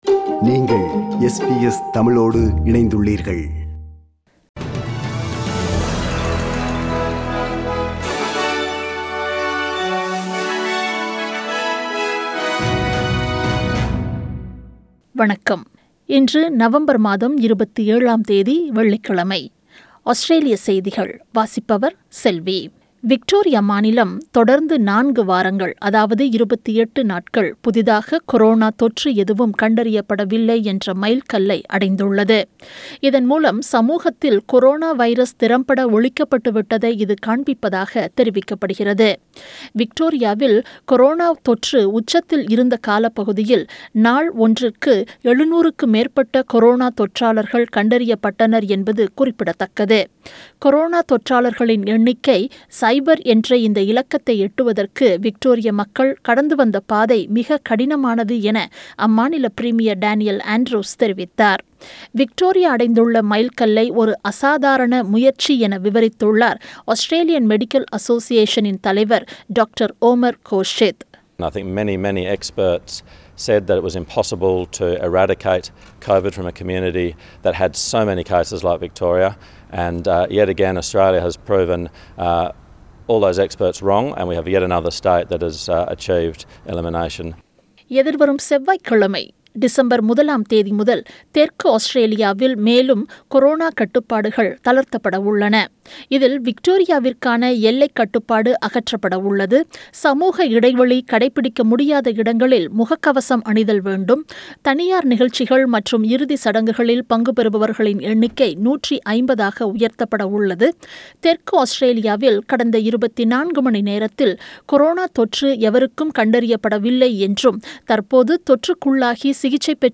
Australian news bulletin for Friday 27 November 2020.